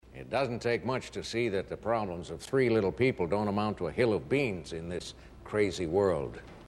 OLD MOVIE QUOTES